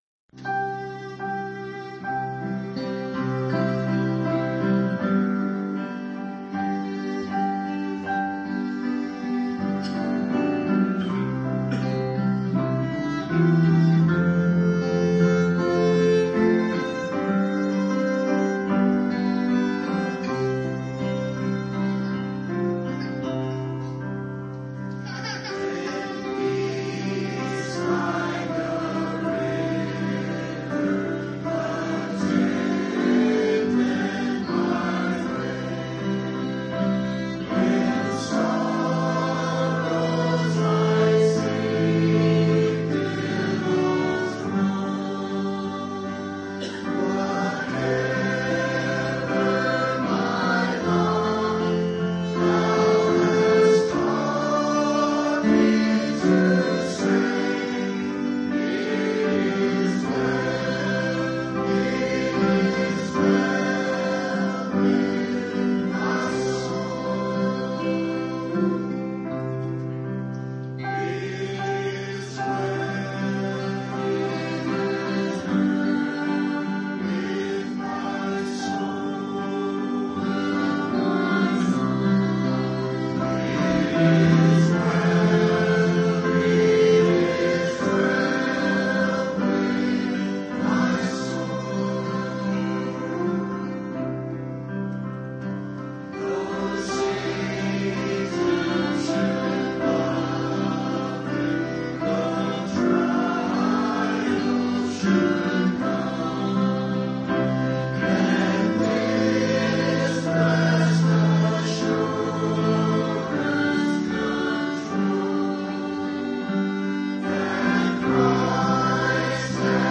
This series is a verse-by-verse exposition of 1 Timothy.